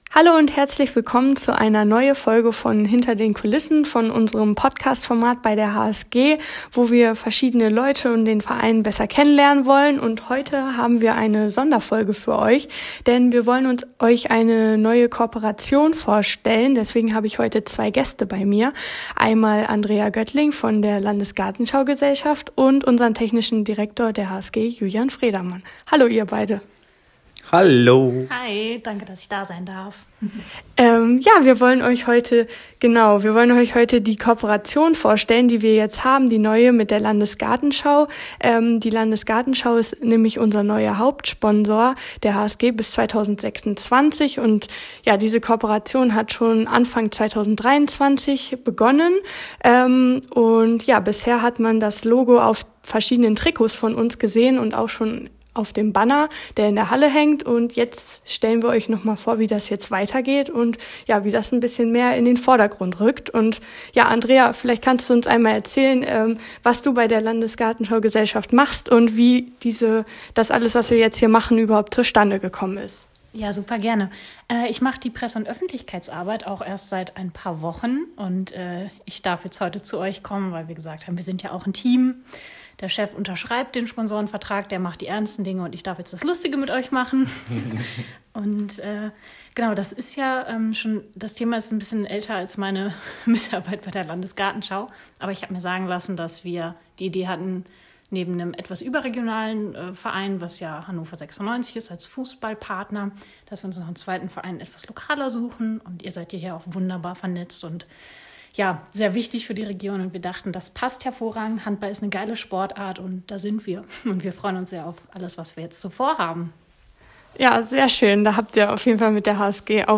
In dem Podcast sind zwei Gäste zu hören